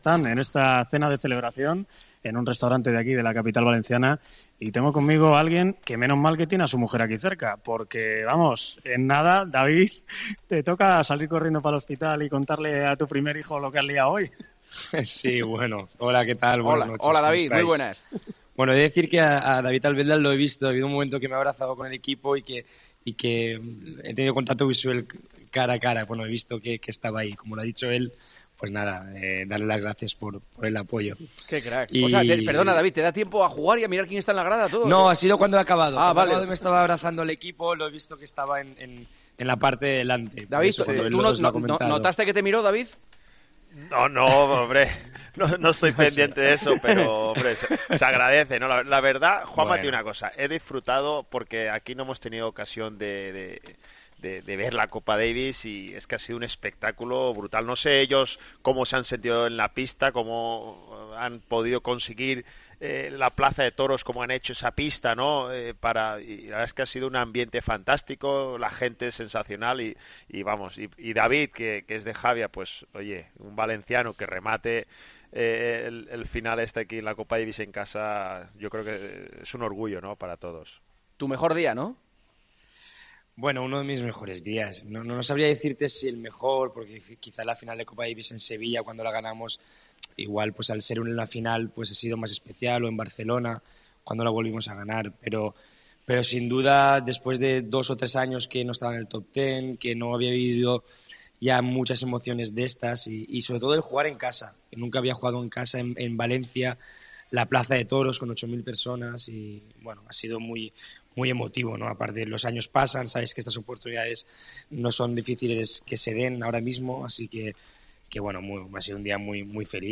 David Ferrer atendió a Tiempo de Juego durante la cena de celebración del equipo español de Copa Davis .